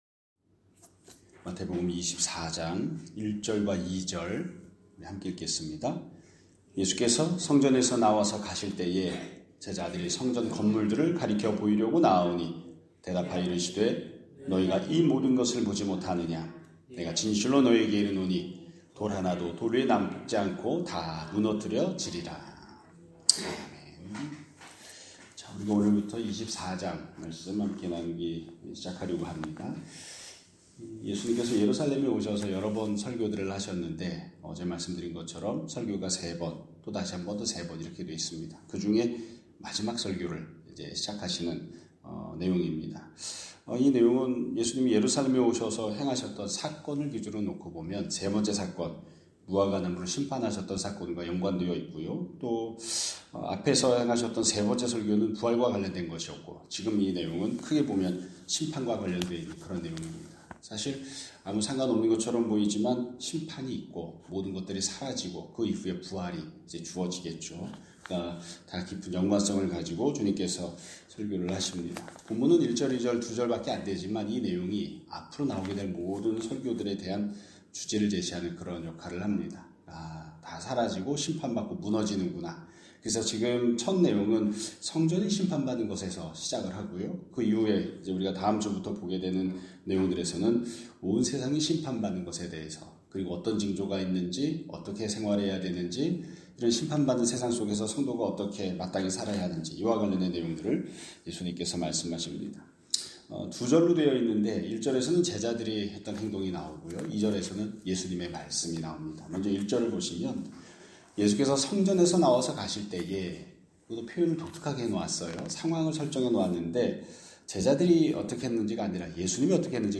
2026년 3월 6일 (금요일) <아침예배> 설교입니다.